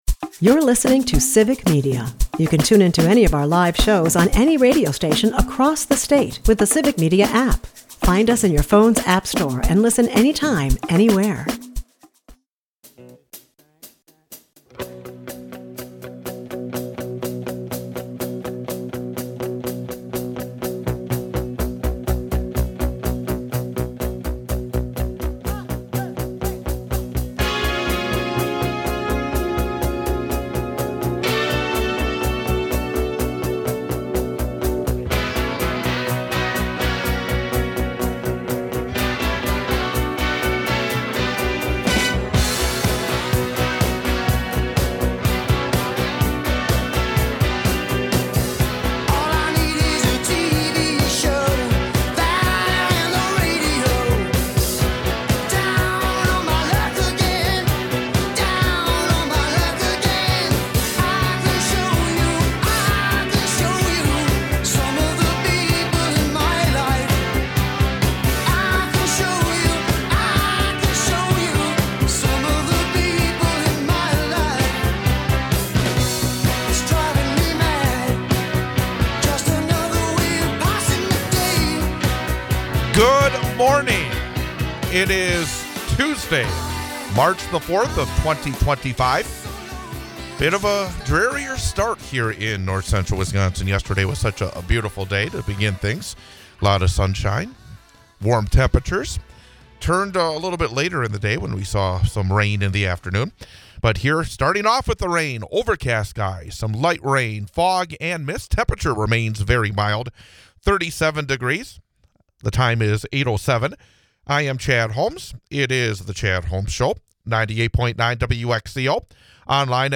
We start discussion of a mayoral veto of a Wausau City Council resolution concerning medicaid funding. A caller brings up topics such as the need for peaceful protest, the firing of former NFL punter Chris Kluwe for standing up against MAGA and Ukraine.